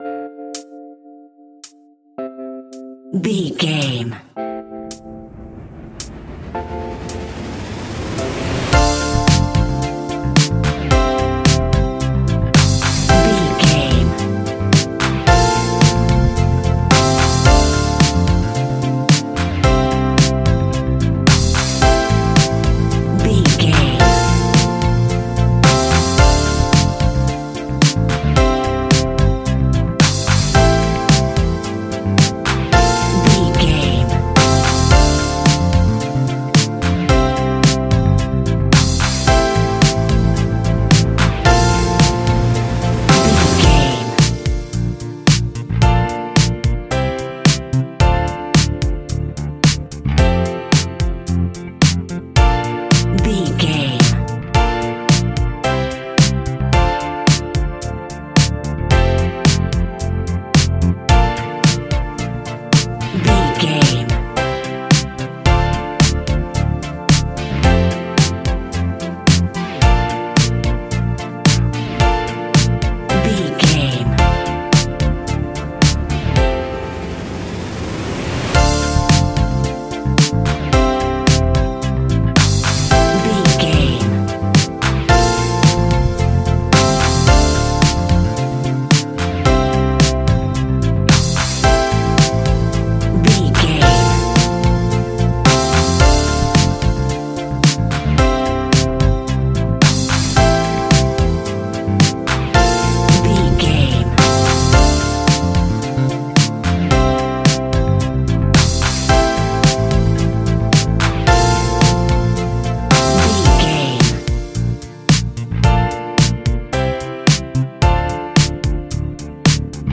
Ionian/Major
ambient
electronic
new age
chill out
downtempo
soundscape
synth
pads